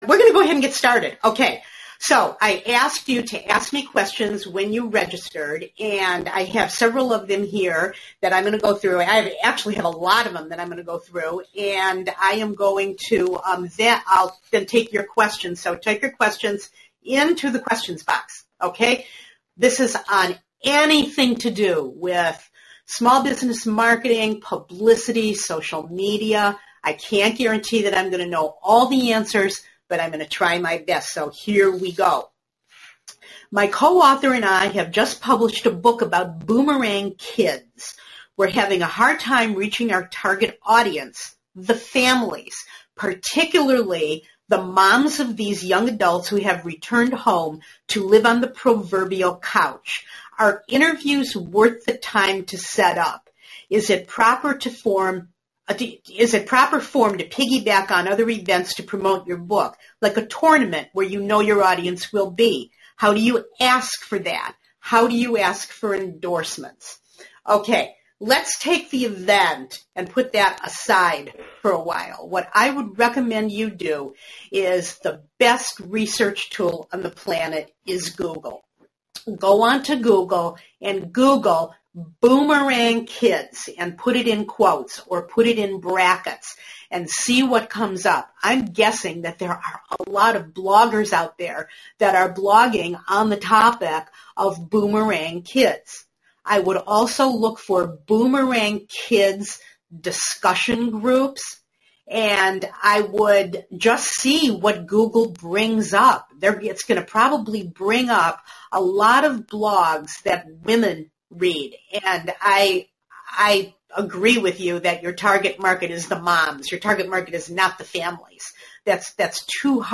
If you missed my first “Ask Me Anything” live session on Saturday, not to worry.